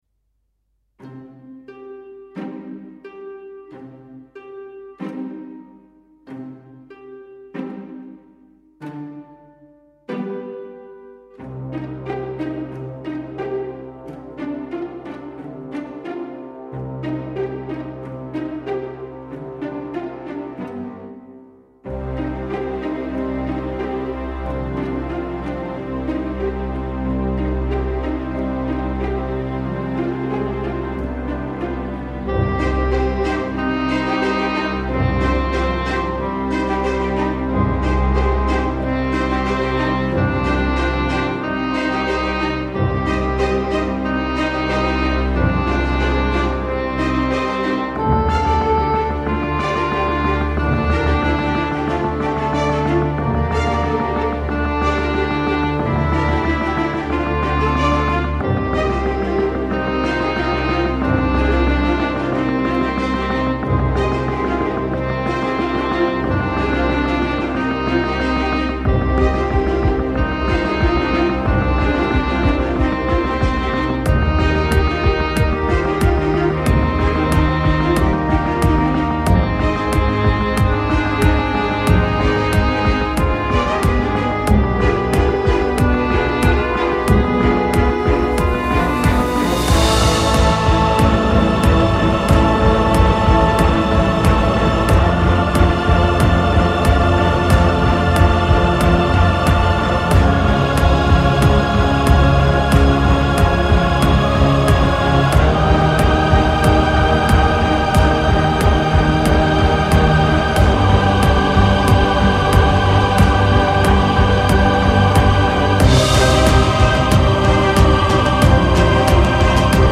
nappes - pizzicati - epique - violons - guerres